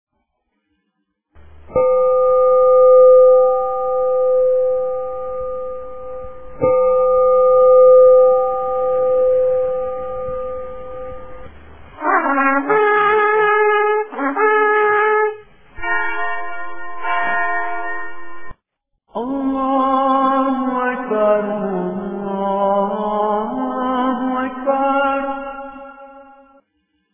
Now we have decided to make this call more universal and use an audio which combines sounds from the four major religions on earth (Mystical Paths, Judaism, Christianity and Islam) to Call to Prayer.